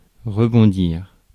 Ääntäminen
IPA: [ʁə.bɔ̃.diʁ]